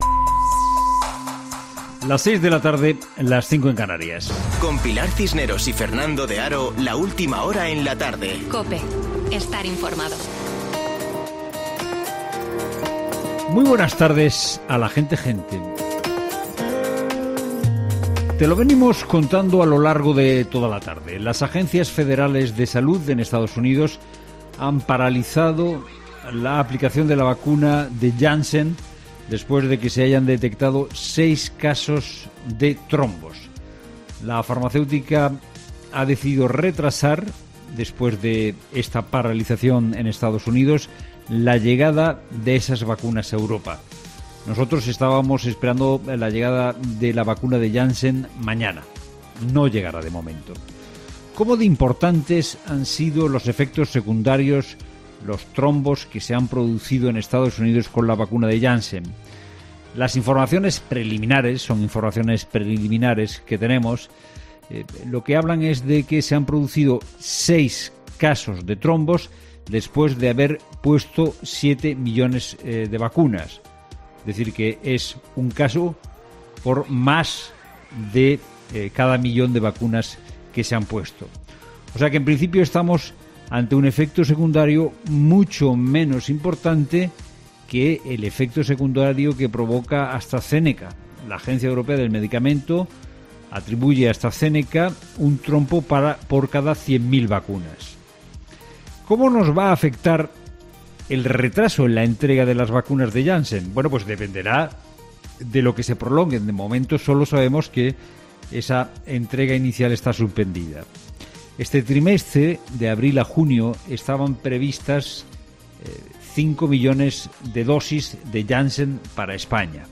Boletín de noticias del 13 de abril de 2021 a las 18.00 horas